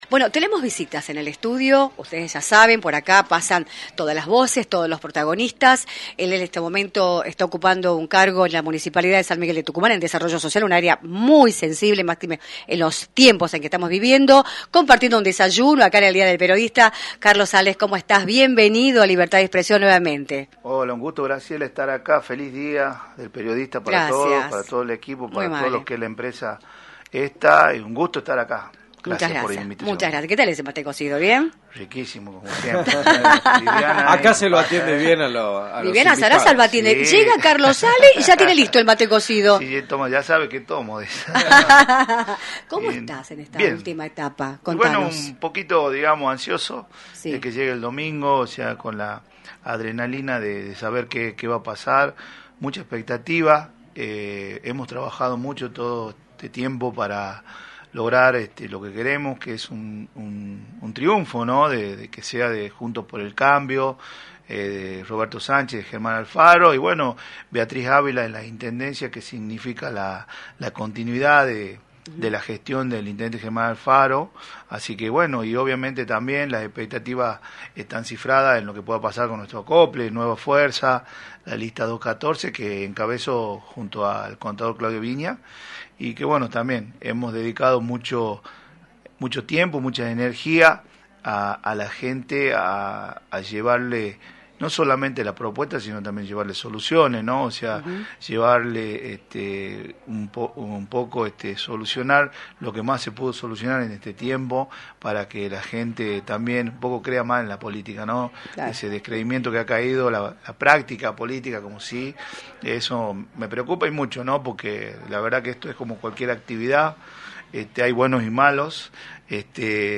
Carlos Ale, Secretario de Desarrollo Social y Cultural de la Municipalidad de San Miguel de Tucumán y candidato a Concejal de la Capital por el espacio Nueva Fuerza, de Juntos por el Cambio, visitó los estudios de “Libertad de expresión”, por la 106.9, para analizar el escenario electoral y político de la provincia, en la antesala de las elecciones establecidas para el domingo 11 de junio.